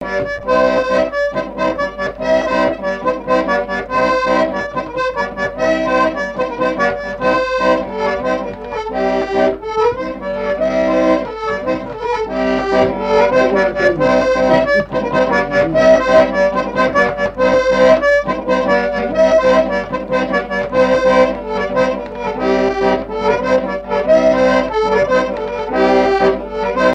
Mémoires et Patrimoines vivants - RaddO est une base de données d'archives iconographiques et sonores.
danse : branle : courante, maraîchine
Pièce musicale inédite